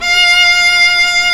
Index of /90_sSampleCDs/Roland - String Master Series/STR_Viola Solo/STR_Vla1 % marc